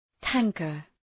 Προφορά
{‘tæŋkər}